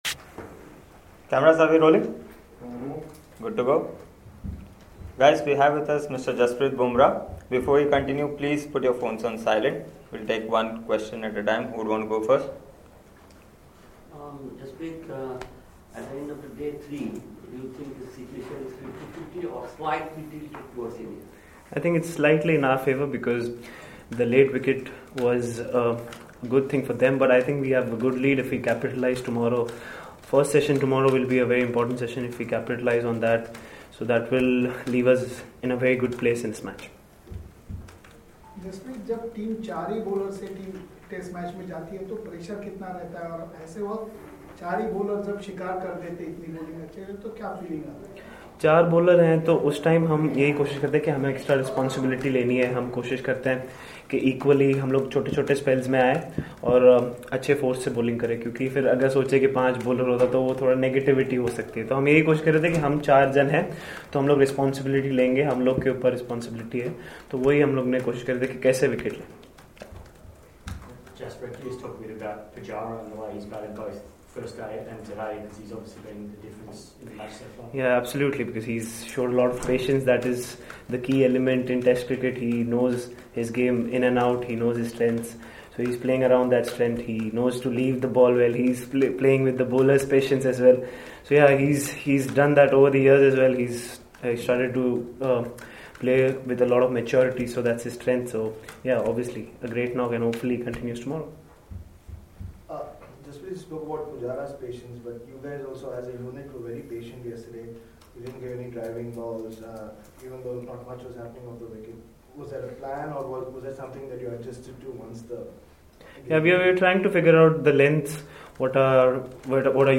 Jasprit Bumrah, Member, Indian Cricket Team, speaks with the media on Saturday, December 8 at the Adelaide Oval after Day 3 of the 1st Test against Australia.